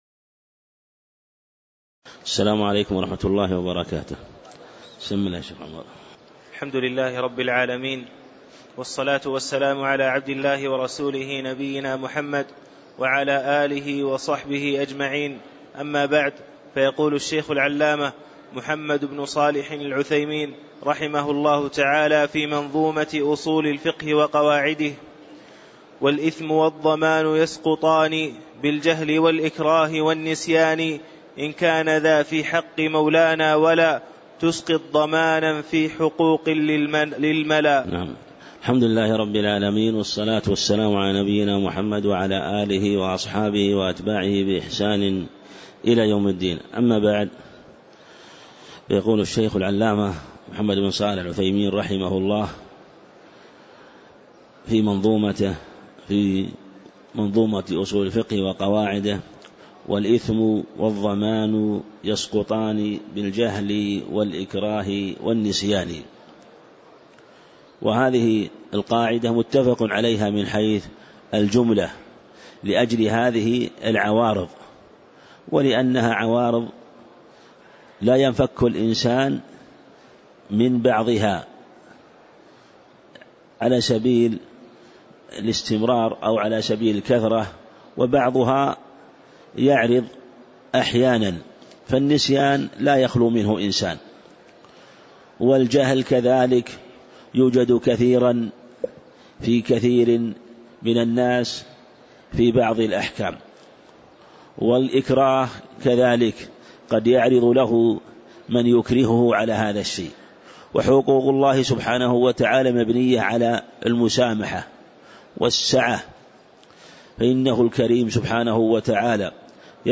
تاريخ النشر ٨ رجب ١٤٣٨ هـ المكان: المسجد النبوي الشيخ